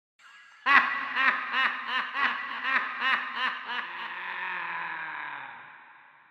دانلود آهنگ خنده ترسناک 1 از افکت صوتی انسان و موجودات زنده
دانلود صدای خنده ترسناک 1 از ساعد نیوز با لینک مستقیم و کیفیت بالا
جلوه های صوتی